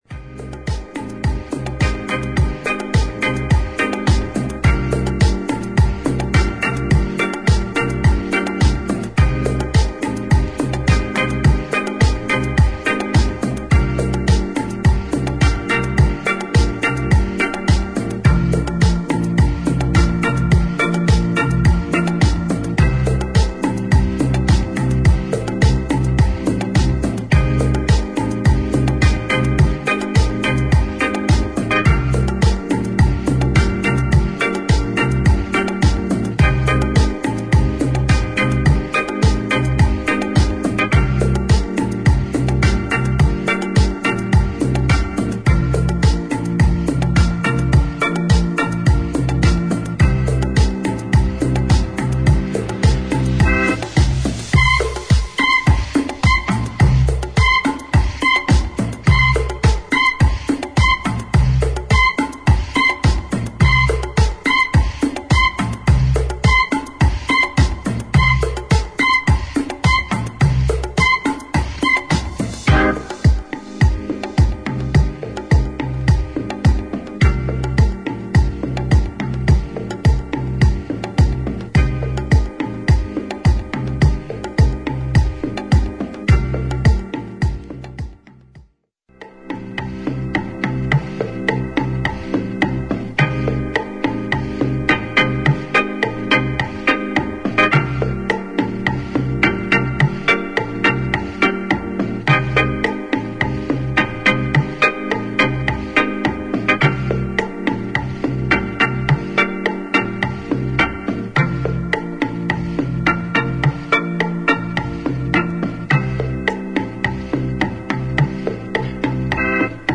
数ある同曲のエディットの中でも、オリジナルの雰囲気を十分に生かした、DJユースな仕上がりになっています。